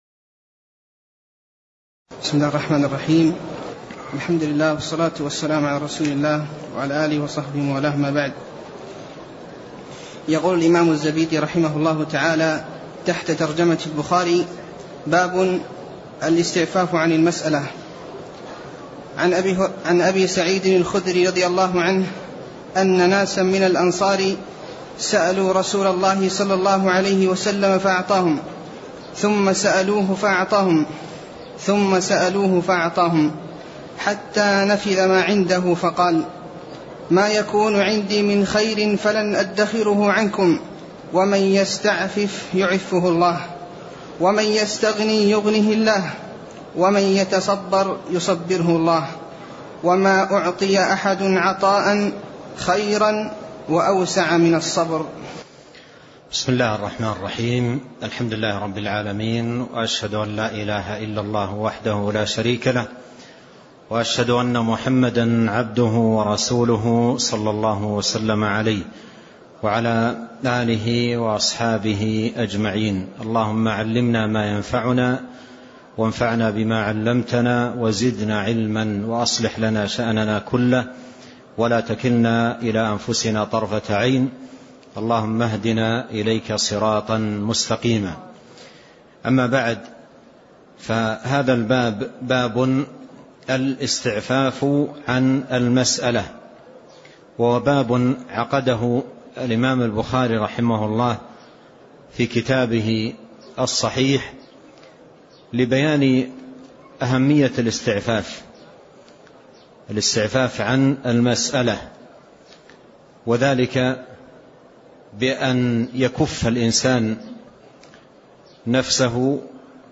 تاريخ النشر ١ رجب ١٤٣٤ هـ المكان: المسجد النبوي الشيخ